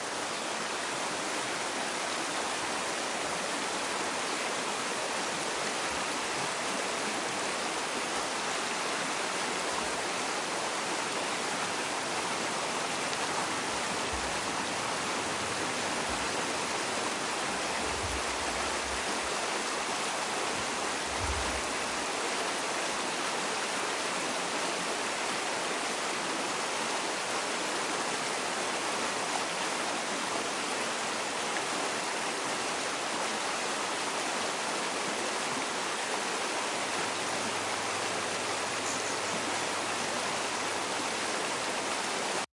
描述：瀑布遥远与鸟唧啾
Tag: 瀑布